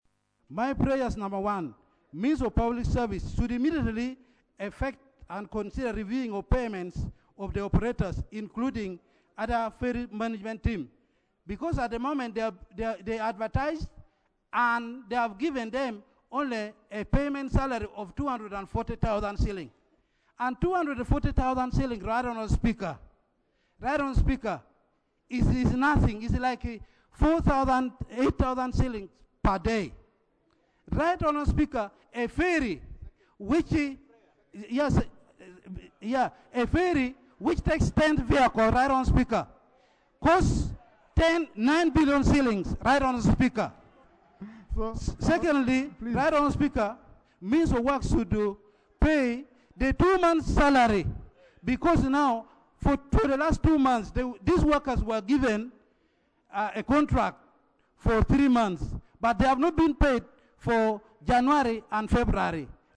Raising on a matter of national importance on Tuesday, 11 March 2025, Maruzi North County Representative, Hon. Nelson Okello (highlighted the dire situation of ferry operators at Masindi Port where traffic he said traffic has been diverted to due to the closure of the bridge.